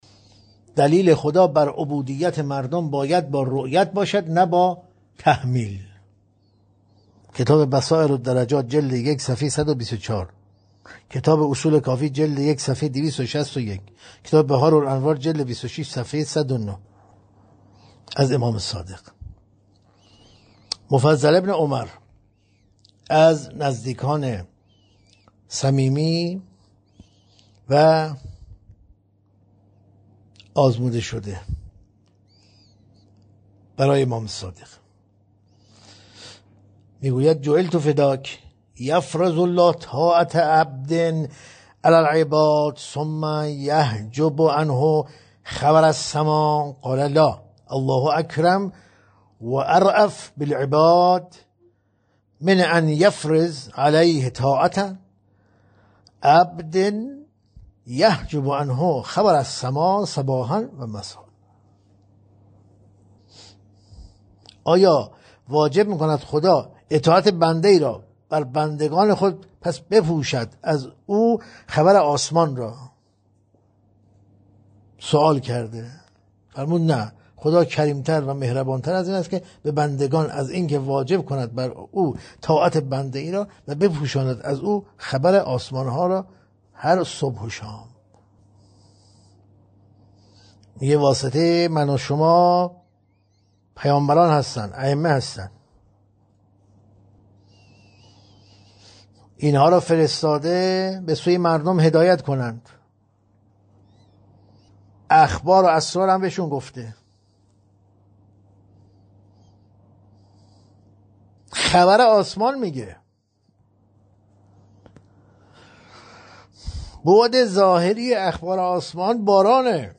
در این بخش، می‌توانید گزیده‌ای از تدریس‌های روزانه